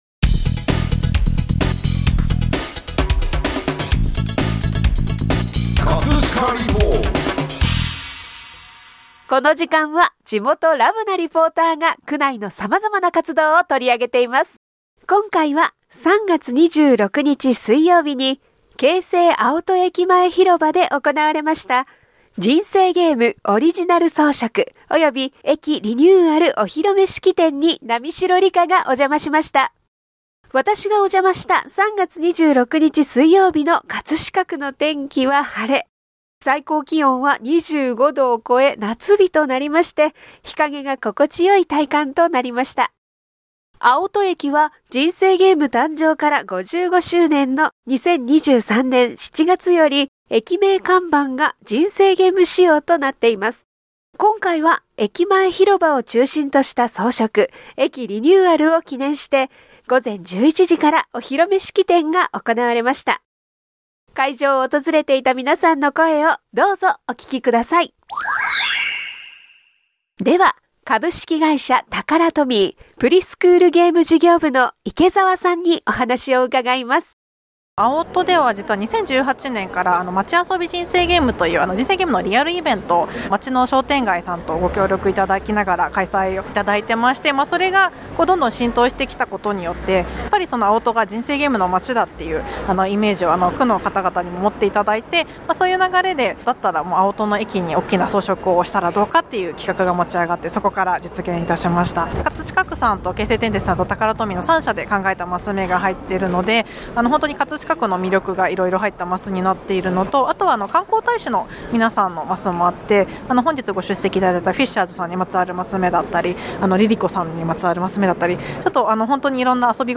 【葛飾リポート】
▼リポート音声